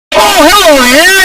mlg-sound-effect-shrek-oh-hello-there-earrape.mp3